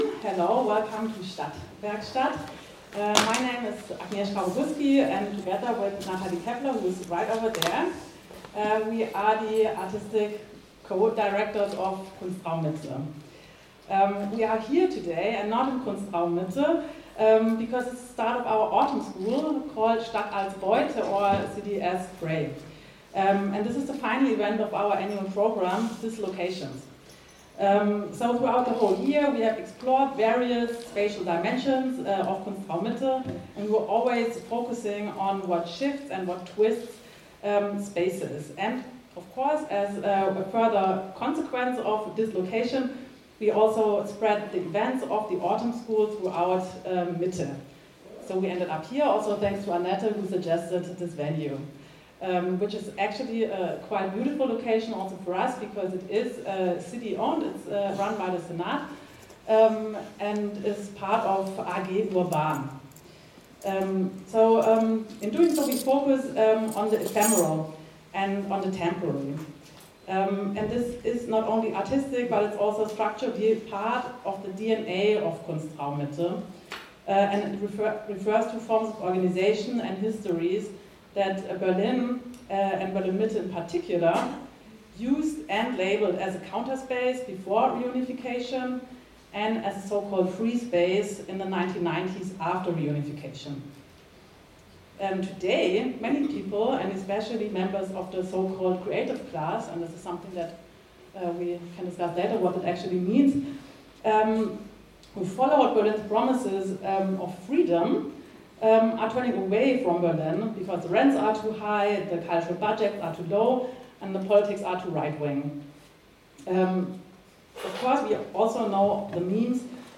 Diese Veranstaltung findet im Rahmen der Autumn School Stadt als Beute Revisited statt.
Gespraech-Stadtwerkstatt-online-audio-converter.com_.mp3